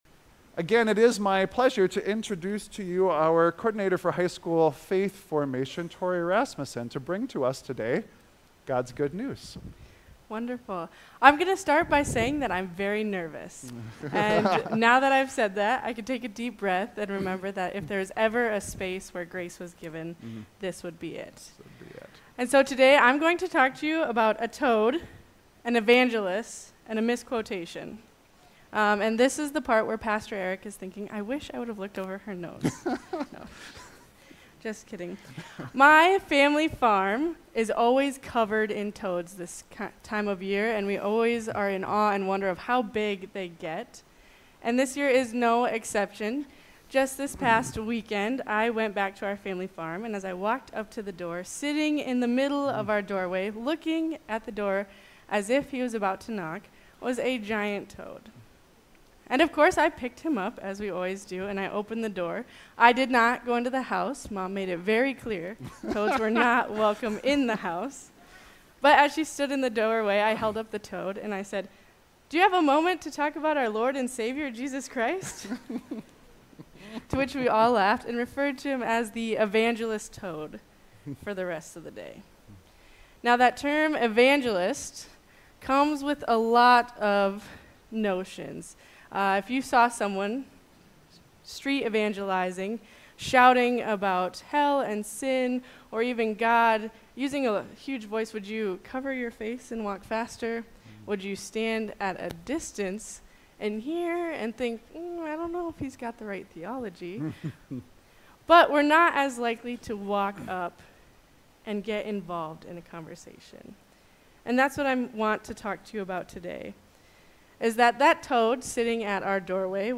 6.29.25-Sermon.mp3